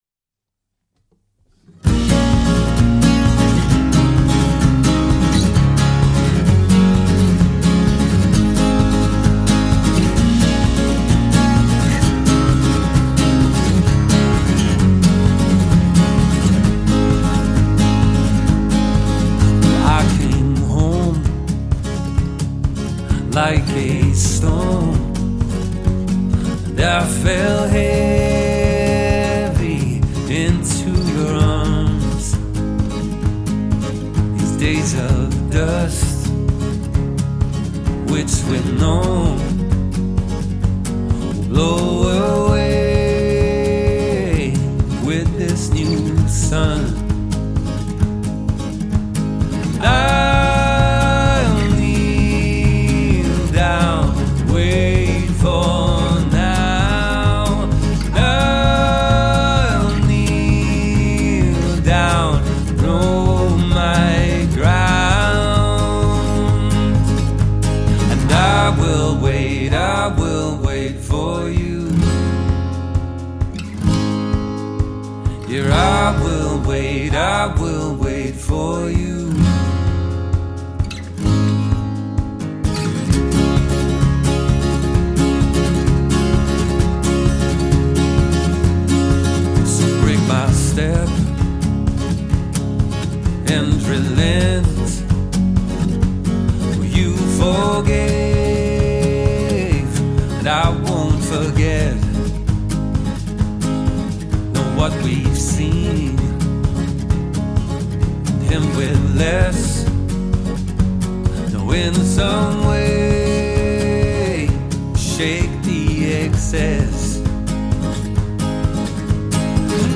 acoustic guitar
a soulful and bluesy voice
gravelly voice
clever rhythmic loop pedal layers